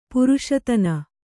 ♪ puruṣatana